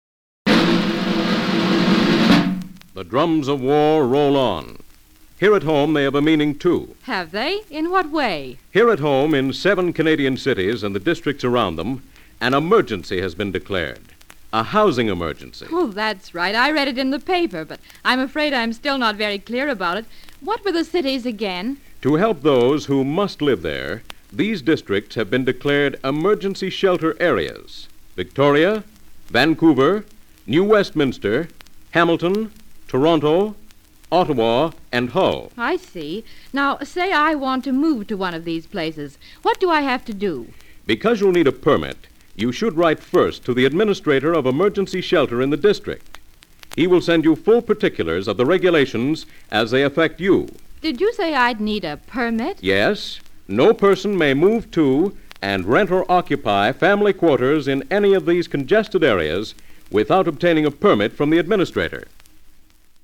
NOW...for the vintage stuff!!!! this material is taken from my private collection of 1940's 16 inch radio transcriptions! these records were what those golden age of radio shows were usually recorded on, as this was before tape!!!! these were HUGE!!! 4 inches wider than a LP, but could only hold about 15 mins a side!!!